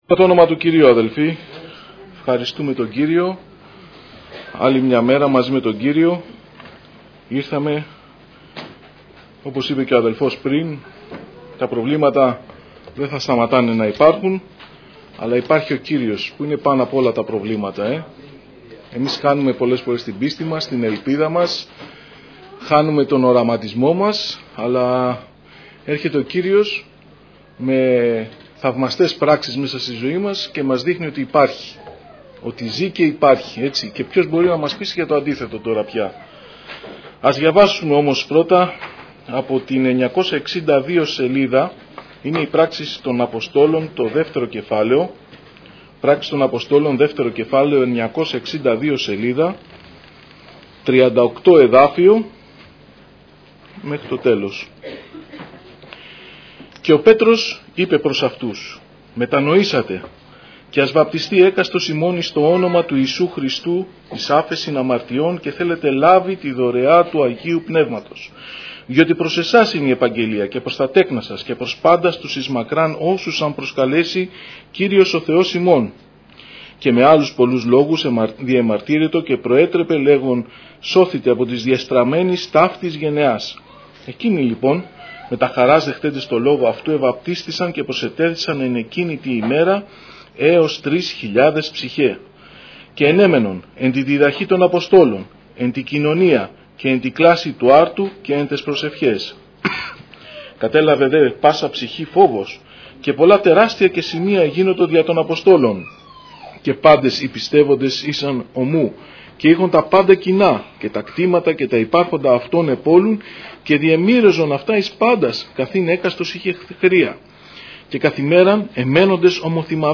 Κηρύγματα Ημερομηνία